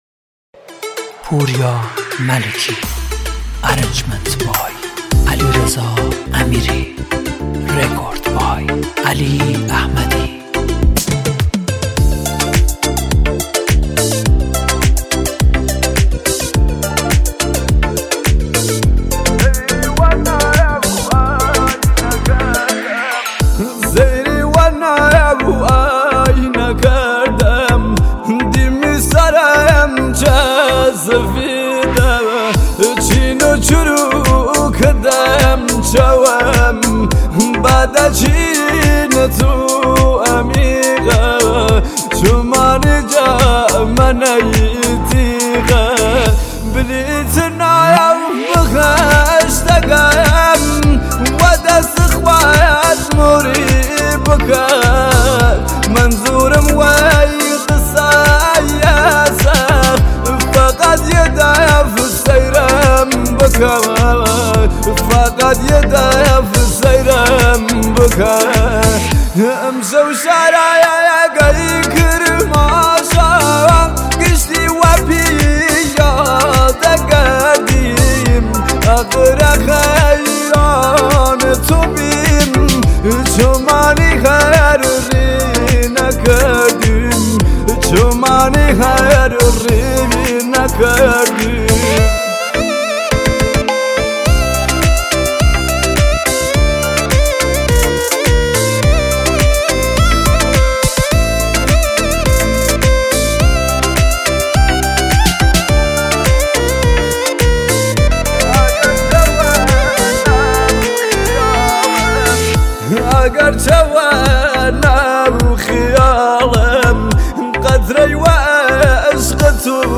ترانه شنیدنی و سوزناک کردی